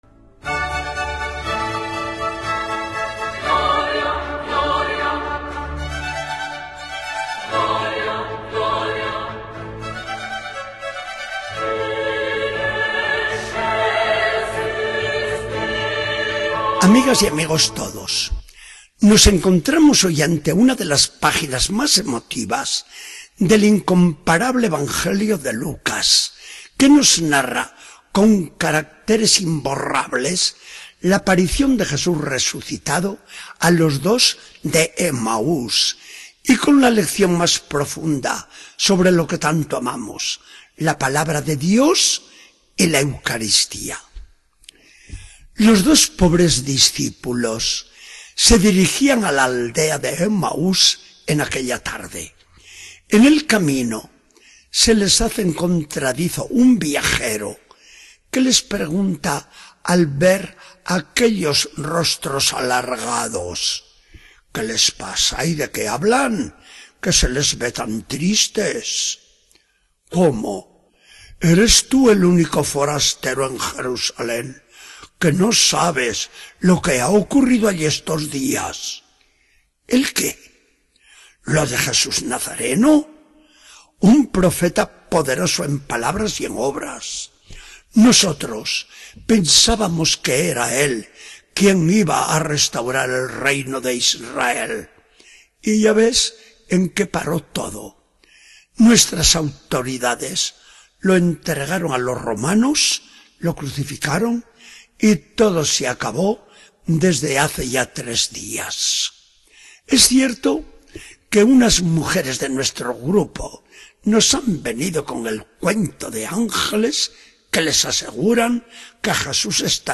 Charla del día 4 de mayo de 2014. Del Evangelio según San Lucas 24, 13-35.